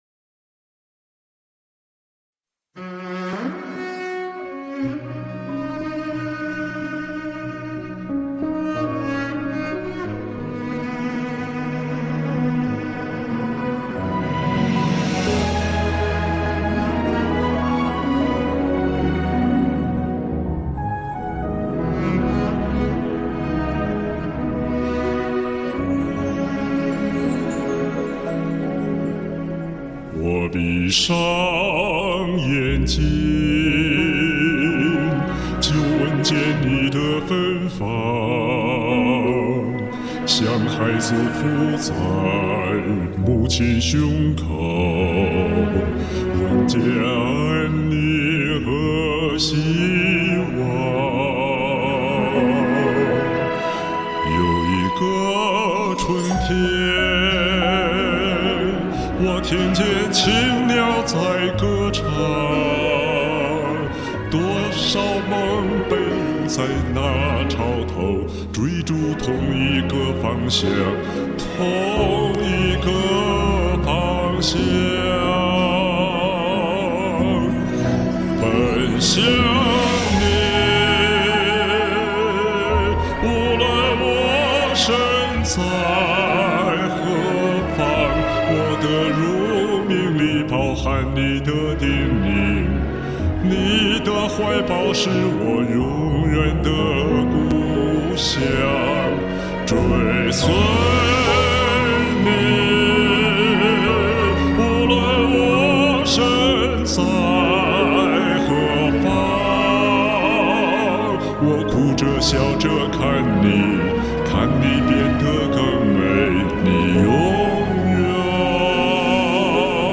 今早用音響一聽與預期不一樣，聲音有點遠，儘管我用的是small space reverb。
低音炮美出天際了！